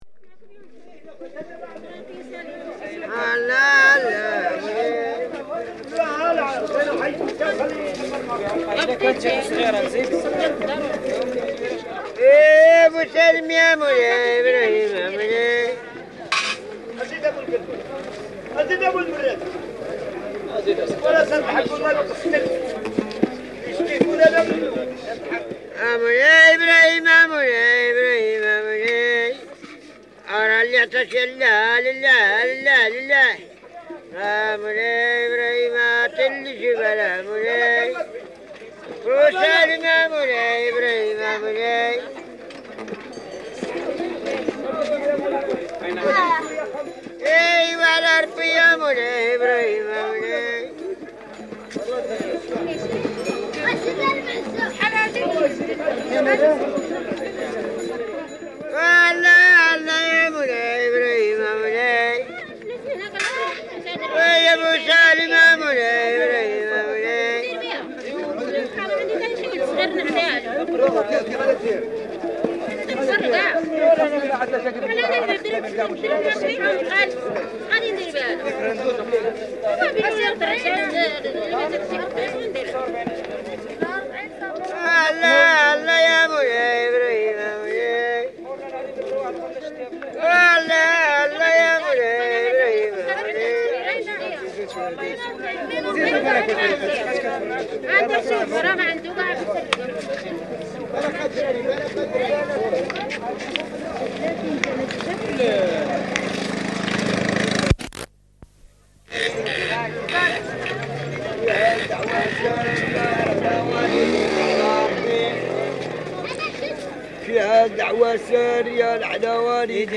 Beggars singing for charity
From the sound collections of the Pitt Rivers Museum, University of Oxford, being from a collection of reel-to-reel tape recordings of Berber (Ait Haddidu) music and soundscapes made by members of the Oxford University Expedition to the Atlas Mountains of Southern Morocco in 1961.